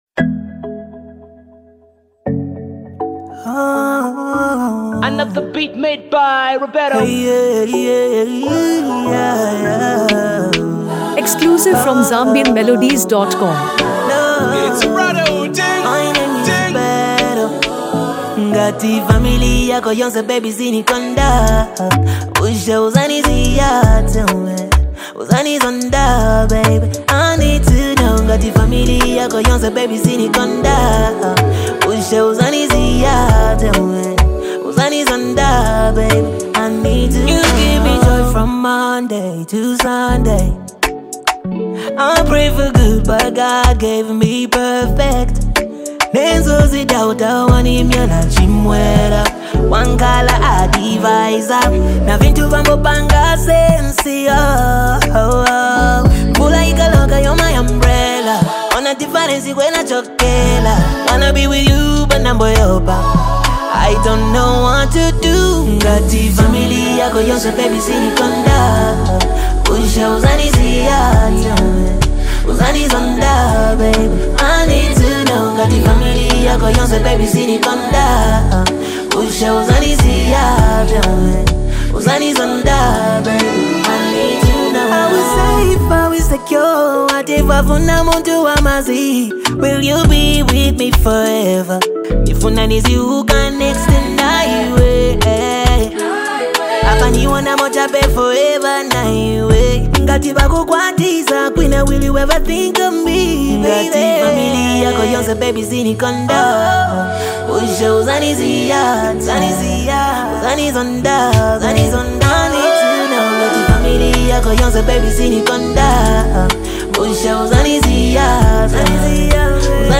Genre: RnB / Afro-Beats